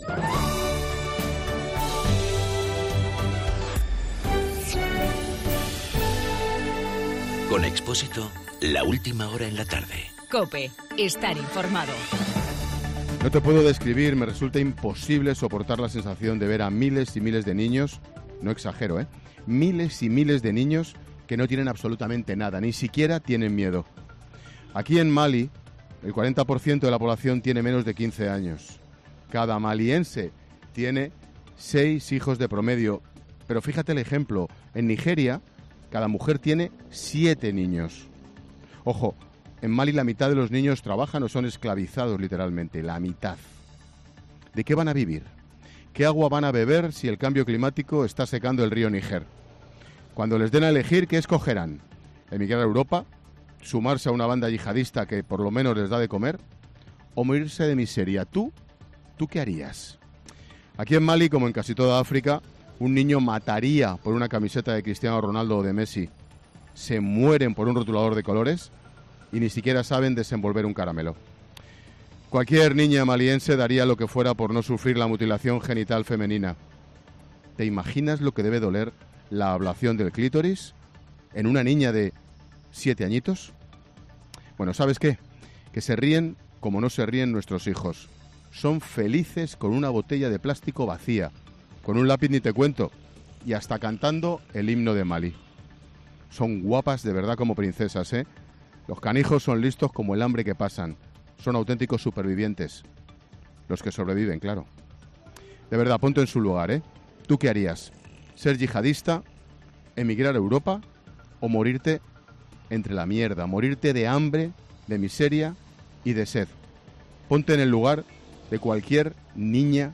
Monólogo de Expósito
Comentario de Ángel Expósito desde Mali sobre la situación de los niños en el país.